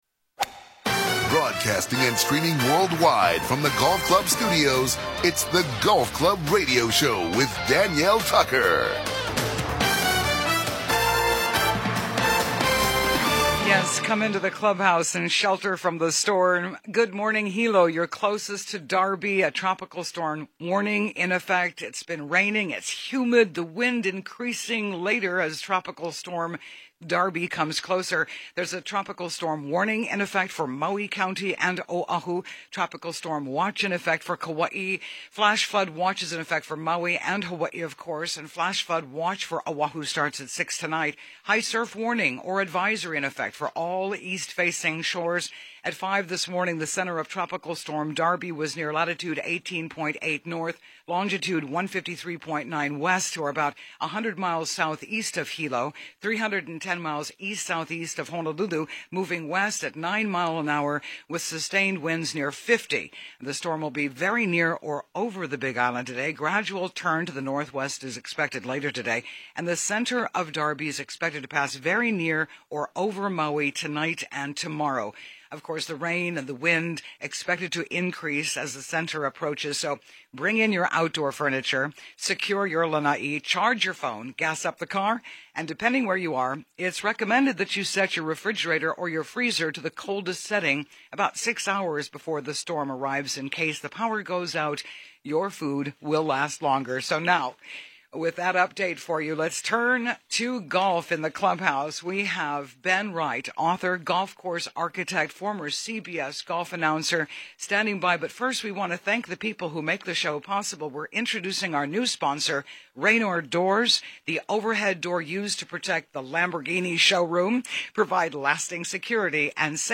Ben Wright: Golf Course Designer , Author and Commentator